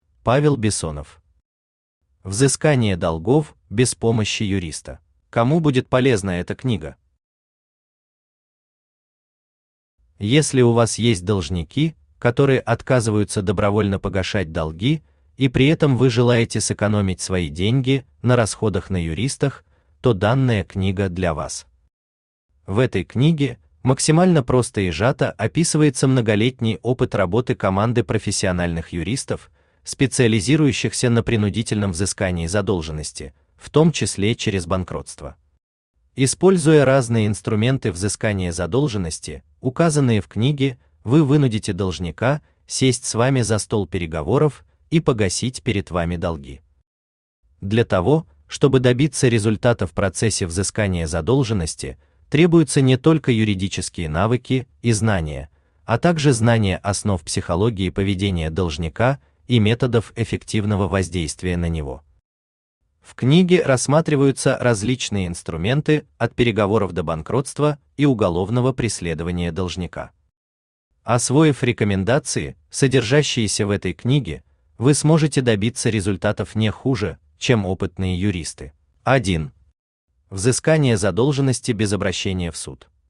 Aудиокнига Взыскание долгов без помощи юриста Автор Павел Бессонов Читает аудиокнигу Авточтец ЛитРес.